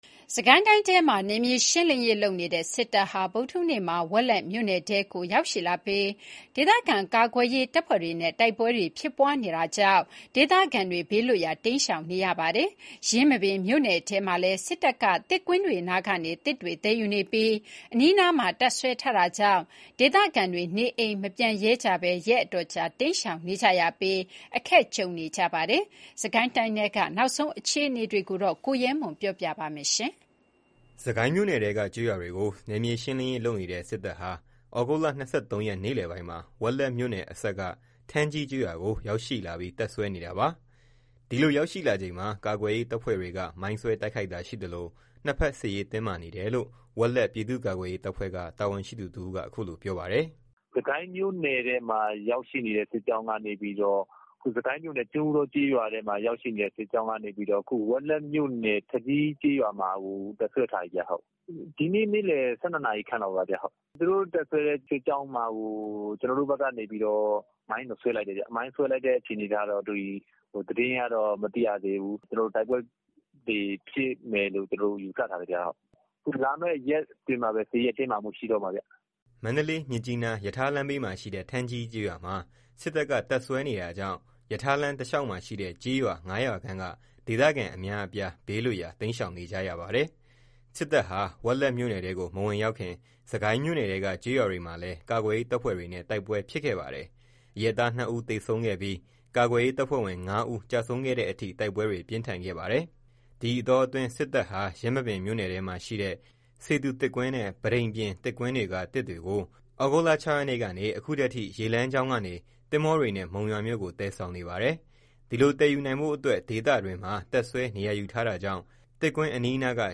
စစ်ကိုင်းမြို့နယ်ထဲကကျေးရွာတွေကို နယ်မြေရှင်းနေတဲ့စစ်တပ်ဟာ သြဂုတ်လ ၂၃ ရက်နေ့လယ်ပိုင်းမှာ ဝက်လက်မြို့နယ်အစပ်က ထန်းကြီးကျေးရွာကို ရောက်လာပြီးတပ်စွဲထားပါတယ်။ ဒီလိုရောက်လာချိန်မှာ ကာကွယ်ရေးတပ်ဖွဲ့တွေက မိုင်းဆွဲတိုက်ခိုက်ခဲ့ပြီး နှစ်ဘက်စစ်ရေးတင်းမာနေတယ်လို့ ဝက်လက် ပြည်သူ့ကာကွယ်ရေးတပ်ဖွဲ့က တာဝန်ရှိသူတဦးက အခုလို ပြောပါတယ်။